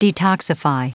Prononciation du mot detoxify en anglais (fichier audio)
Prononciation du mot : detoxify